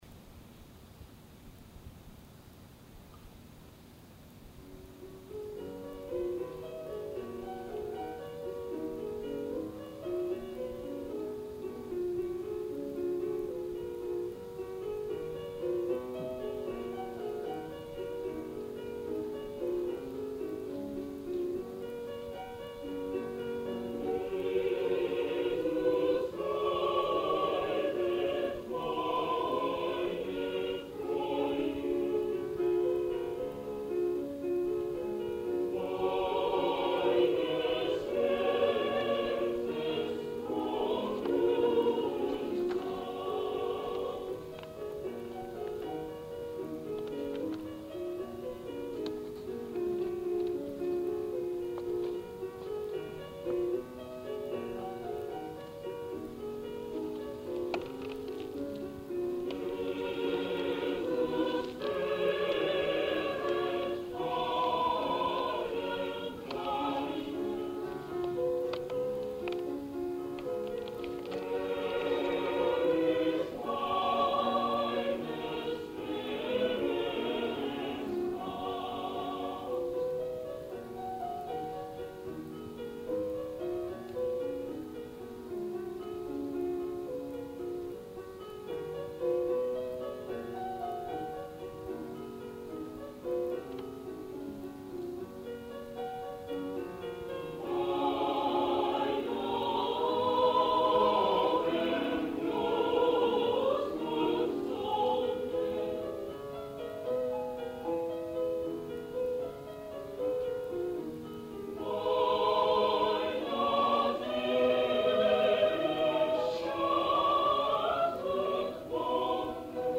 １９９９年６月１３日　東京・中野ゼロホールで
ソプラノ５名、アルト５名、テナー４名、ベース３名の
編成です。男性１名カウンターテナーがアルトです。
お母様が小さなラジカセでテープ録音をして頂き、
バッハ・コラール
合唱団　某大学ＯＢ会合唱団メンバー　１７名